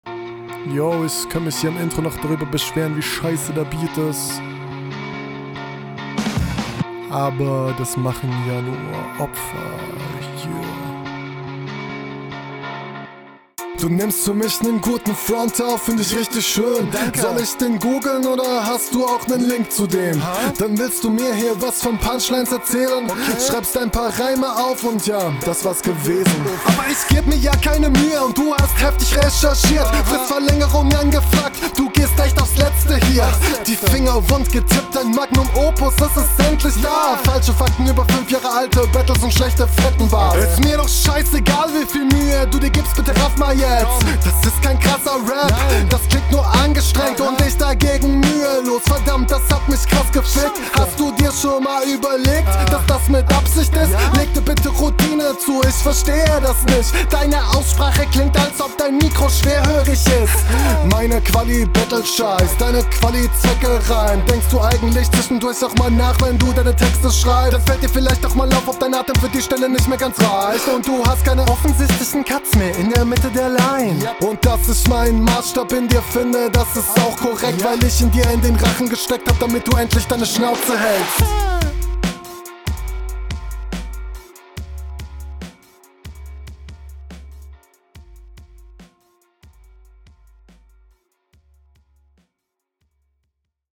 Finde den Flow und das allgemeine Soundbild nicht so cool wie in deiner Hinrunde, aber …